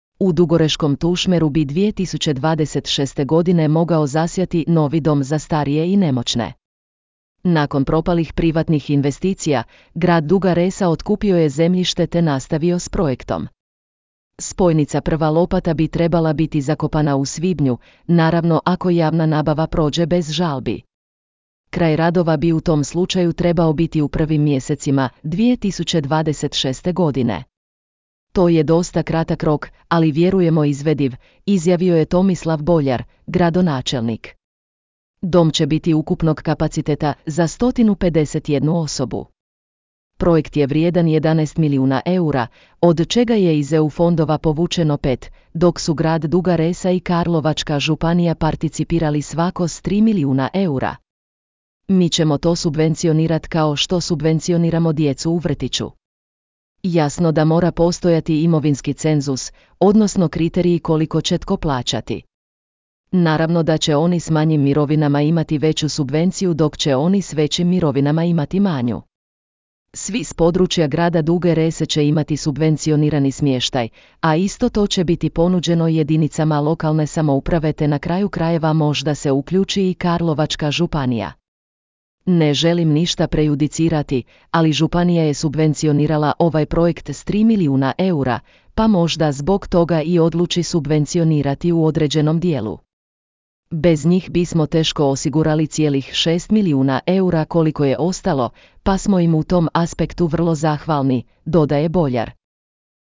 Cijeli razgovor s gradonačelnikom Duge Rese, Tomislavom Boljarom možete pogledati u našoj emisiji Gost dana.